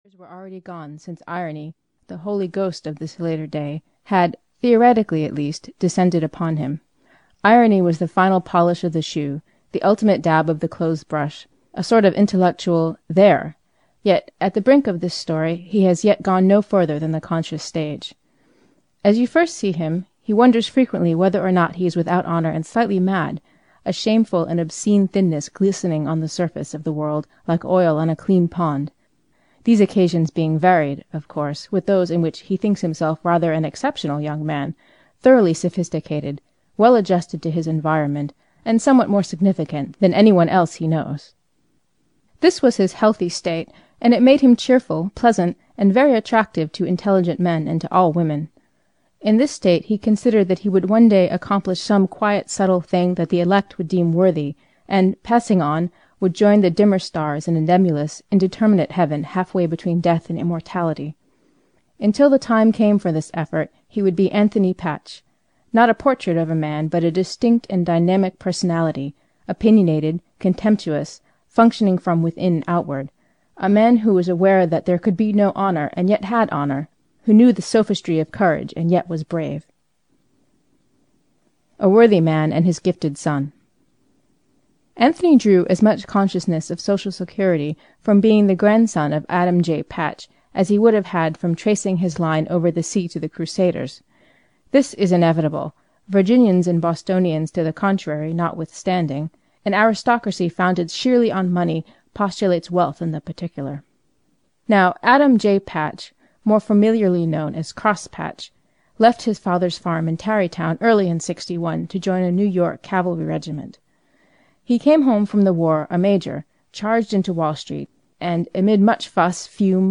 The Beautiful and Damned (EN) audiokniha
Ukázka z knihy